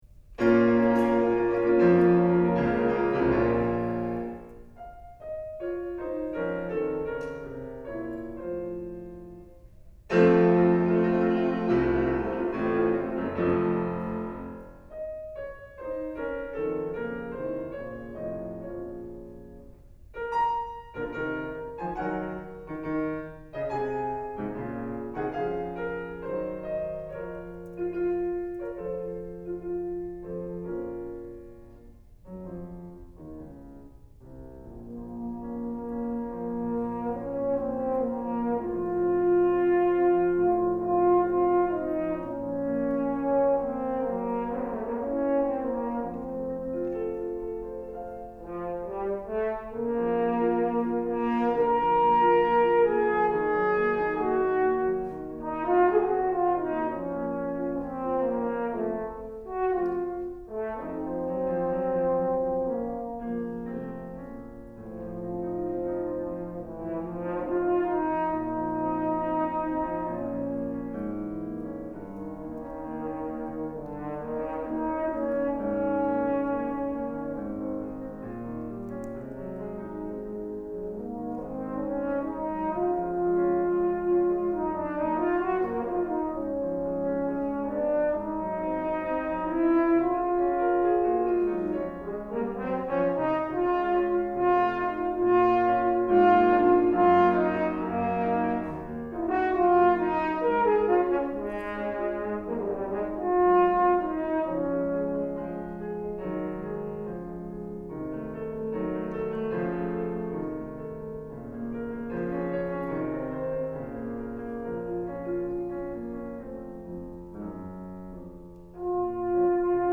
Thoughts on Teaching and Playing the Horn
My faculty recital on Tuesday went very well, and overall I was pleased with the performance.